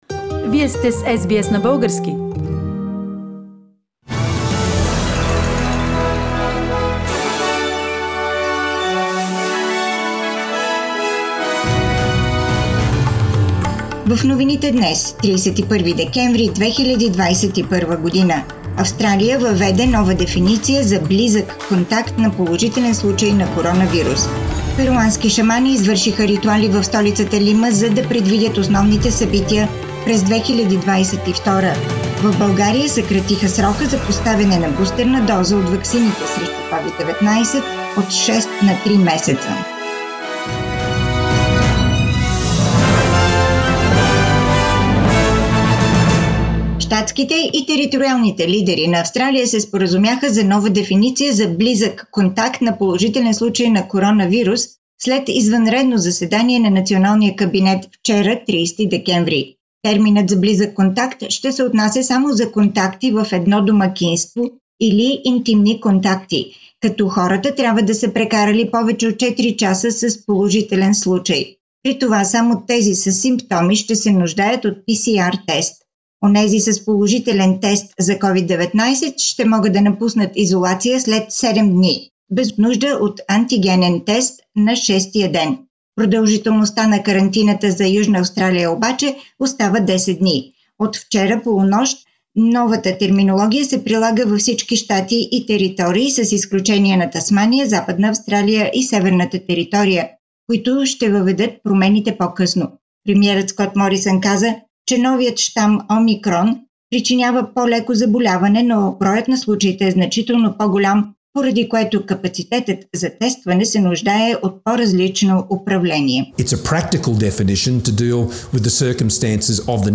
Weekly Bulgarian News – 31st December 2021